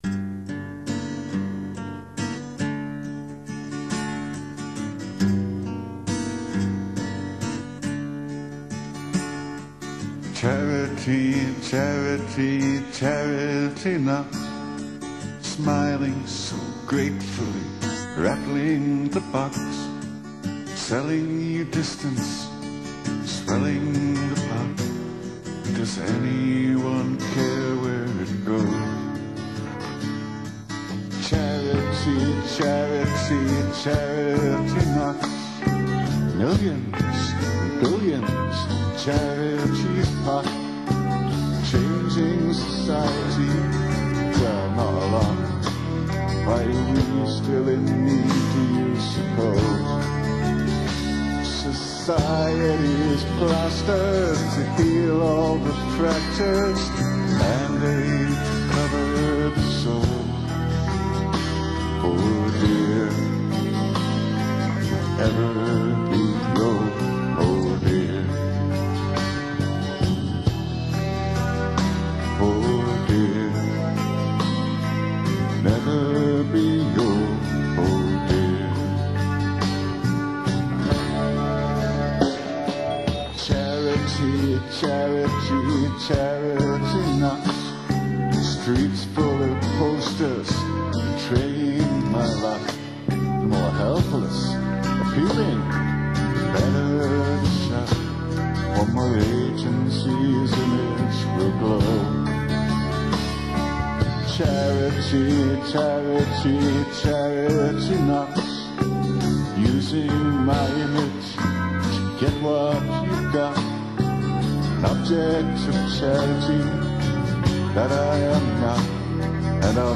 Music is slow acoustic guitar and keyboards.